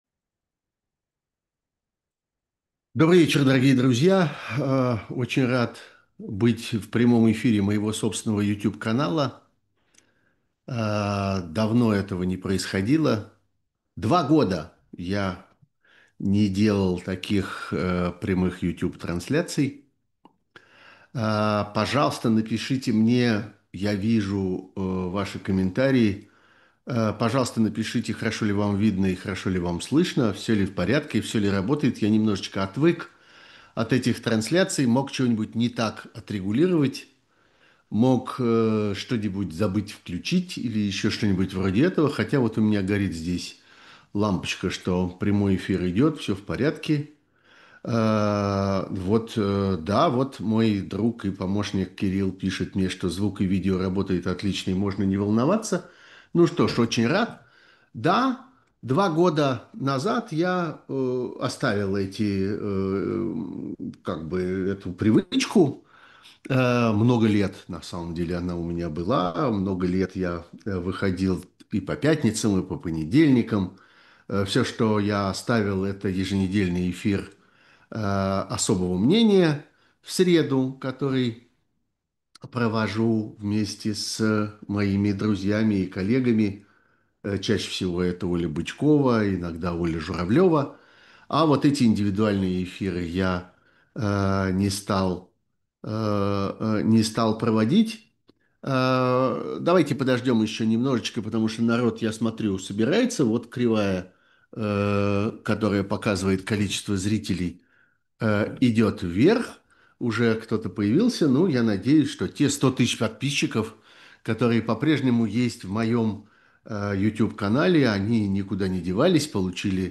Эфир ведёт Сергей Пархоменко